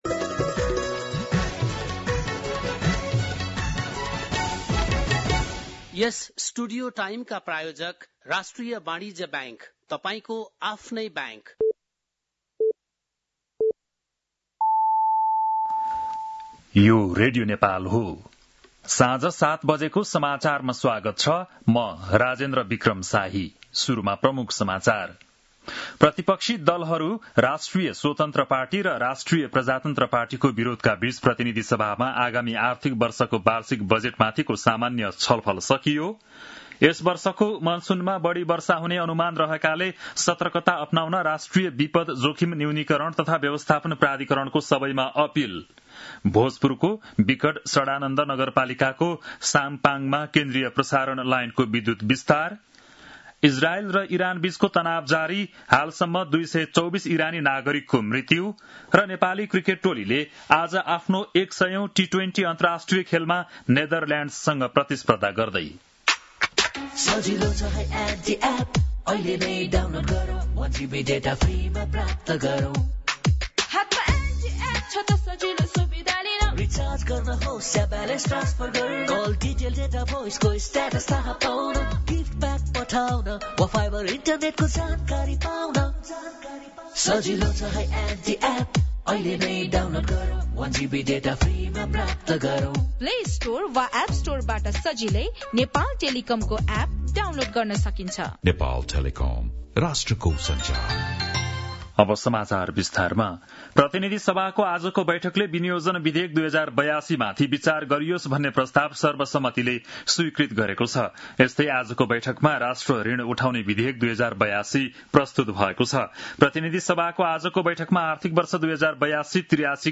बेलुकी ७ बजेको नेपाली समाचार : २ असार , २०८२
7-pm-nepali-news-3-02.mp3